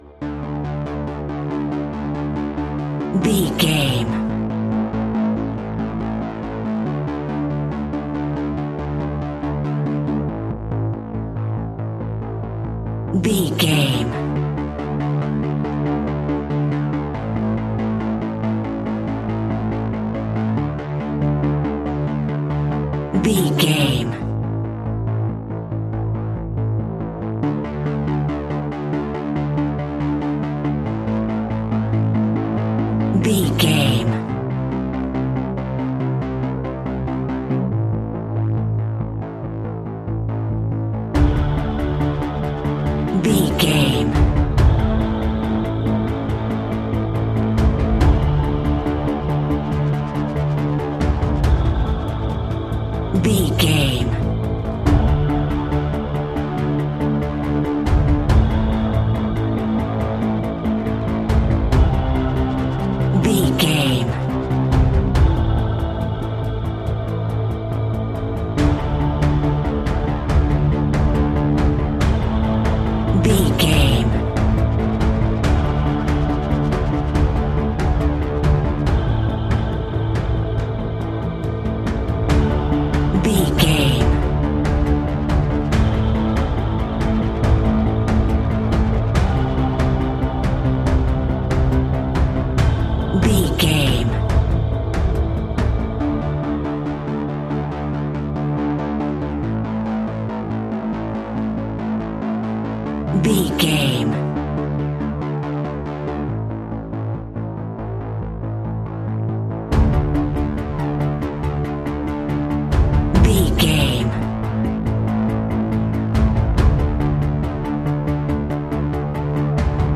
A great piece of royalty free music
In-crescendo
Aeolian/Minor
tension
ominous
eerie
instrumentals
horror music
Horror Pads
horror piano
Horror Synths